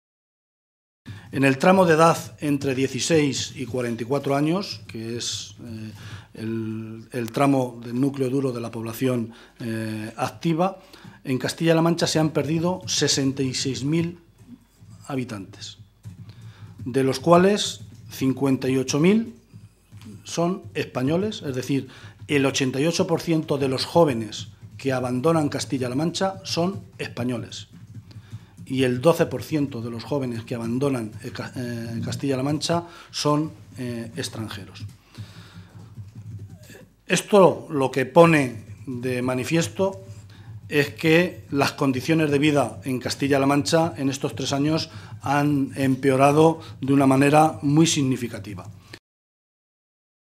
José Luis Martínez Guijarro, portavoz del Grupo Parlametario Socialista
Cortes de audio de la rueda de prensa